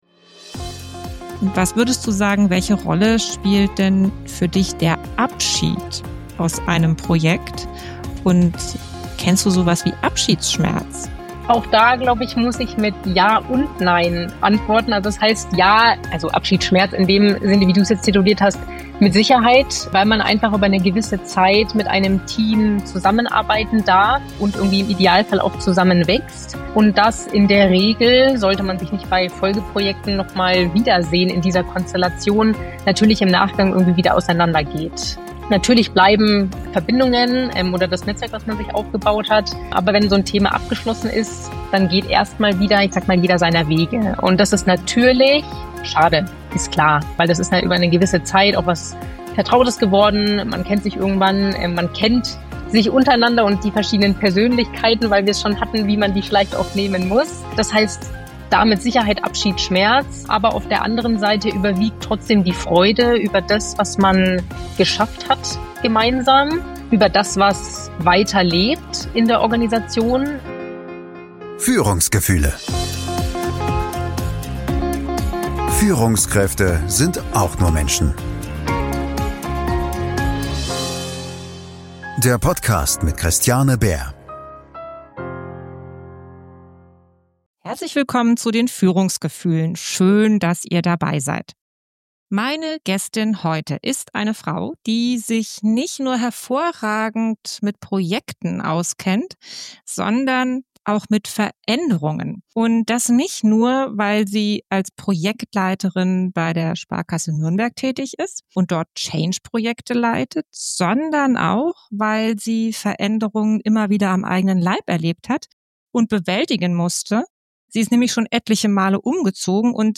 Mach einfach mal – Wie Veränderung uns führt - Gespräch